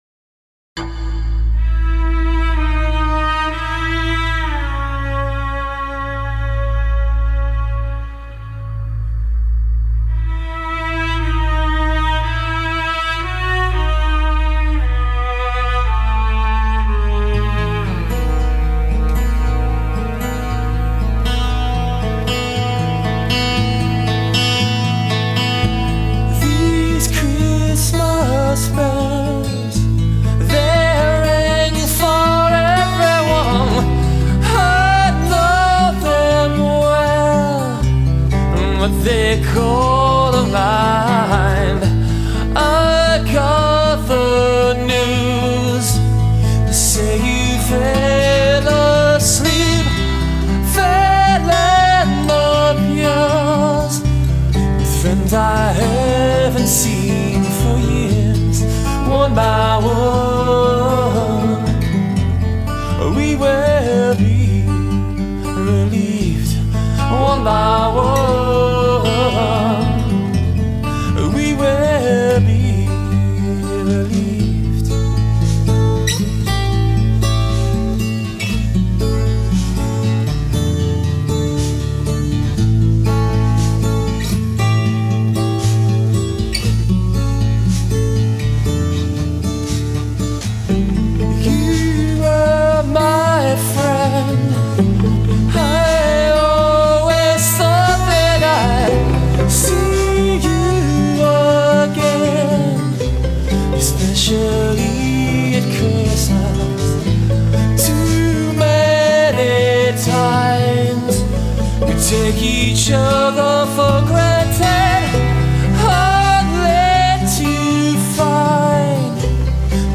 Genre Progressive Pop